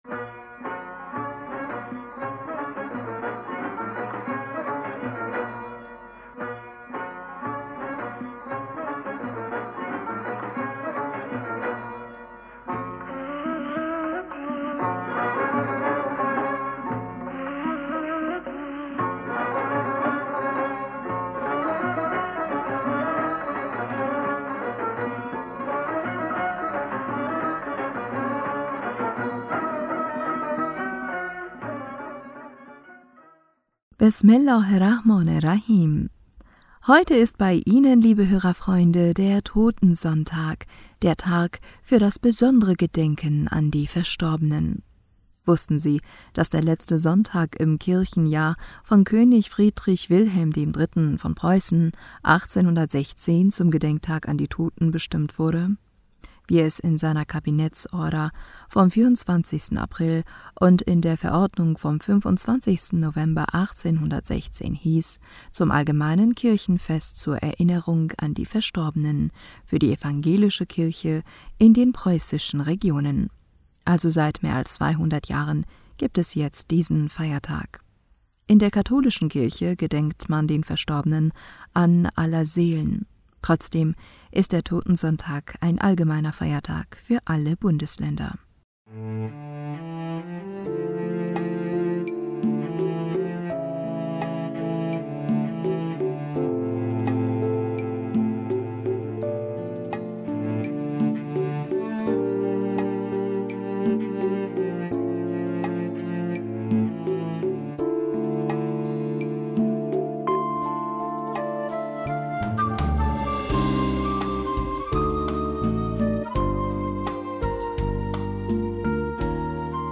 Hörerpostsendung am 26. November 2023.